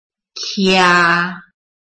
臺灣客語拼音學習網-客語聽讀拼-大埔腔-開尾韻
拼音查詢：【大埔腔】 kia~請點選不同聲調拼音聽聽看!(例字漢字部分屬參考性質)